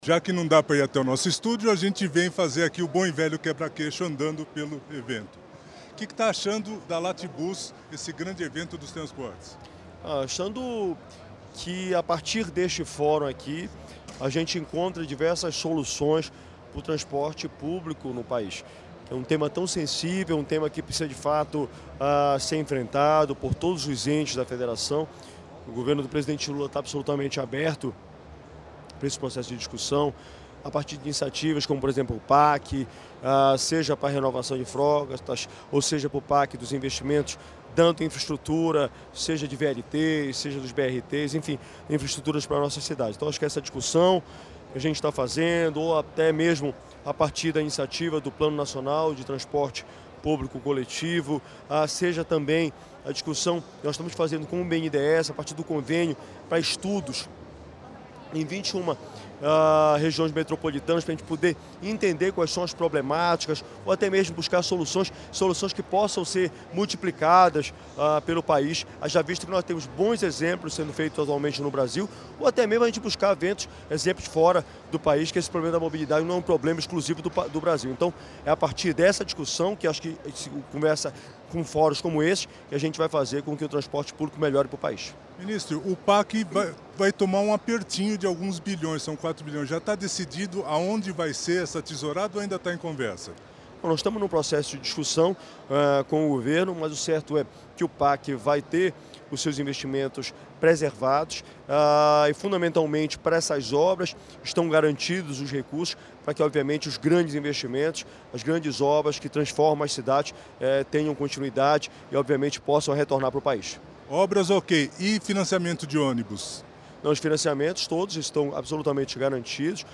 A declaração foi dada ao Diário do Transporte e ao Podcast do Transporte na Lat.Bus, feira de mobilidade que ocorre na cidade de São Paulo e é realizada pela OTM.